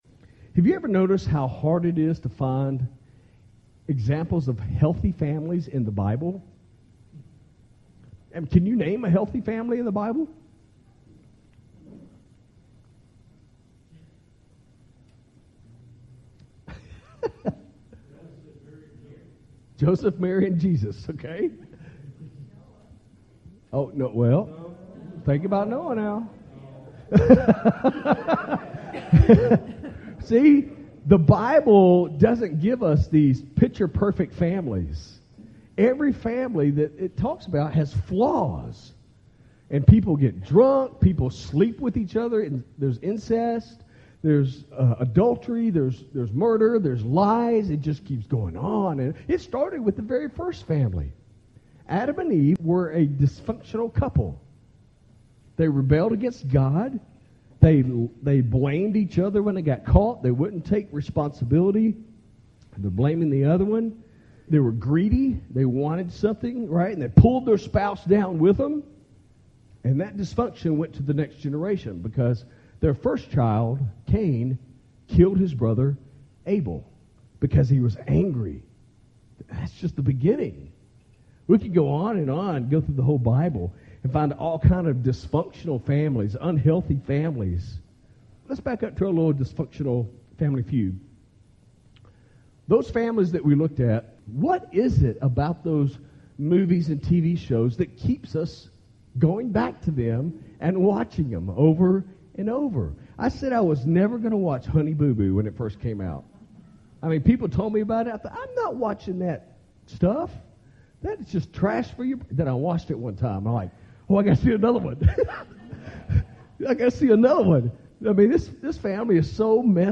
Sermons Archive - Page 2 of 15 - Cordova Community Church